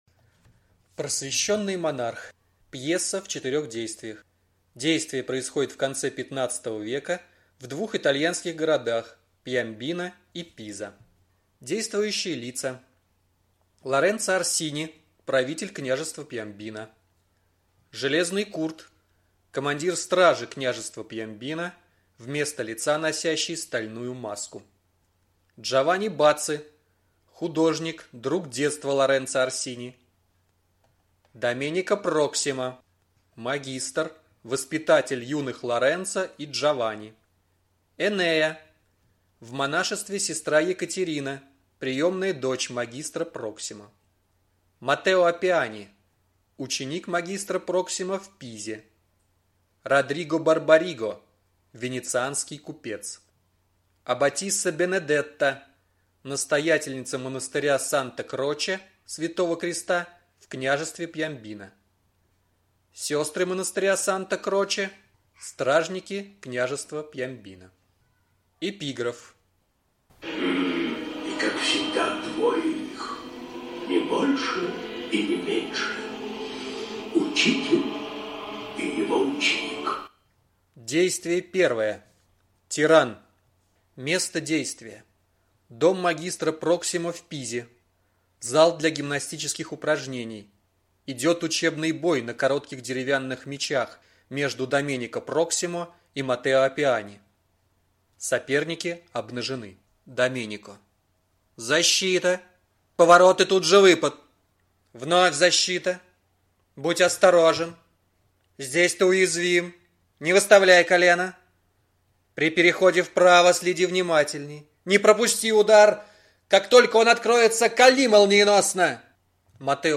Аудиокнига Просвещенный монарх | Библиотека аудиокниг